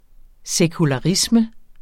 Udtale [ sεkulɑˈʁismə ]